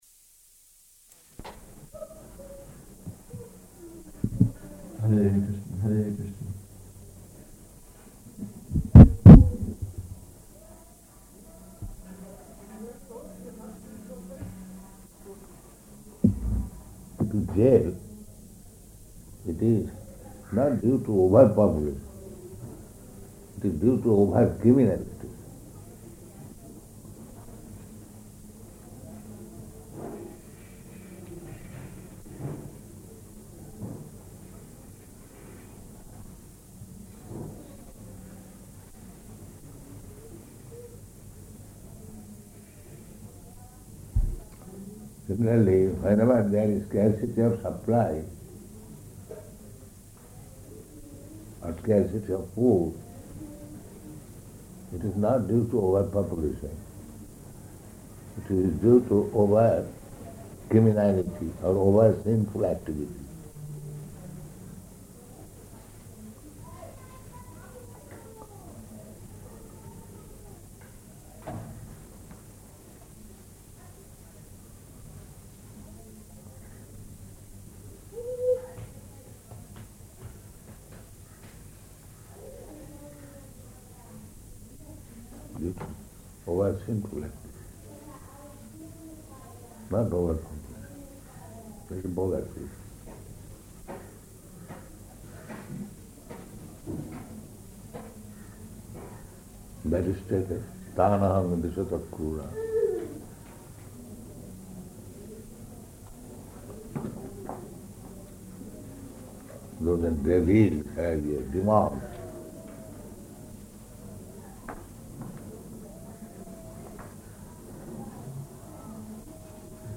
Preparation for Gītā Pratiṣṭhāna (Conference) --:-- --:-- Type: Conversation Dated: December 9th 1976 Location: Hyderabad Audio file: 761209R1.HYD.mp3 Prabhupāda: ...jail, it is not due to overpopulation.